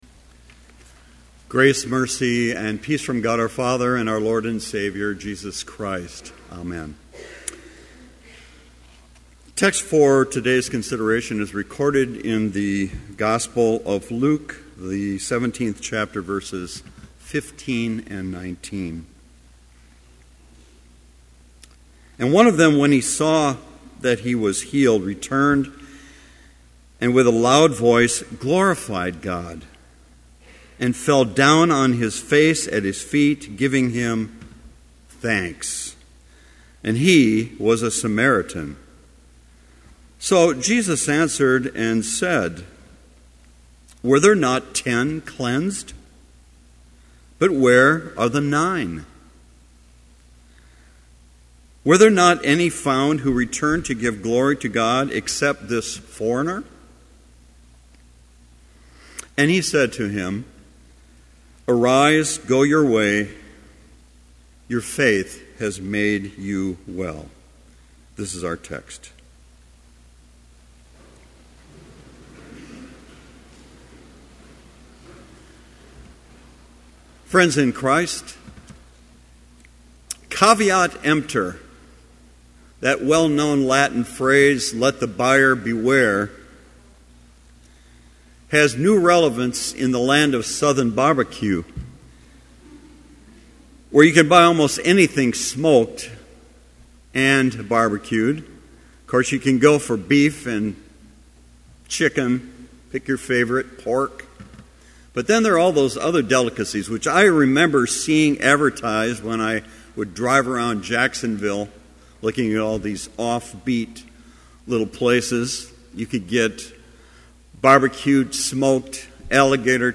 Chapel service on September 28, 2011, at Bethany Chapel in Mankato, MN,
Complete service audio for Chapel - September 28, 2011